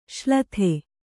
♪ ślathe